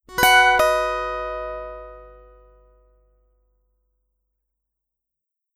news-alert-ding.aiff